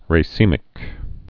(rā-sēmĭk, -sĕmĭk, rə-)